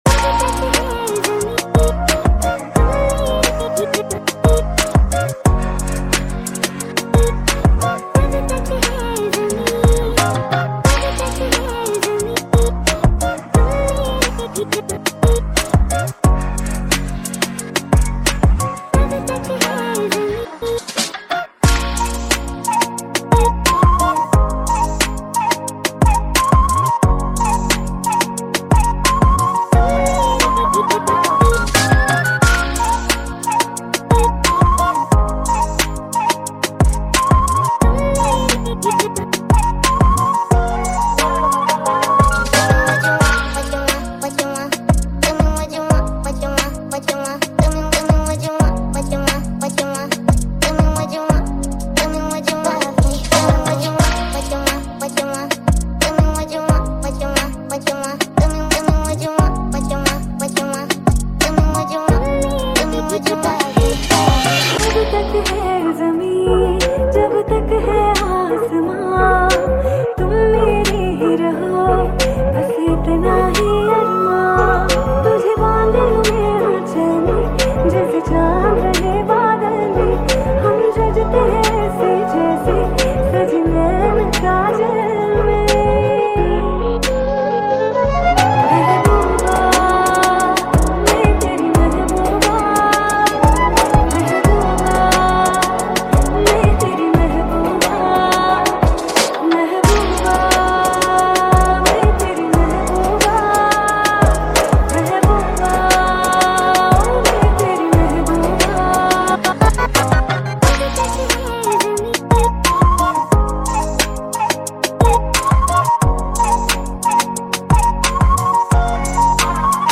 High quality Sri Lankan remix MP3 (2.8).
Rap